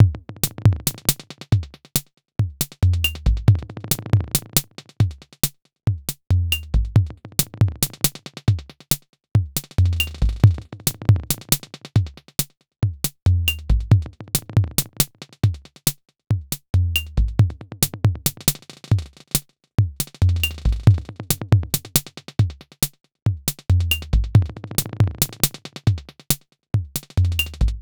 Base de percusión electrónica
Música electrónica
percusión
melodía
sintetizador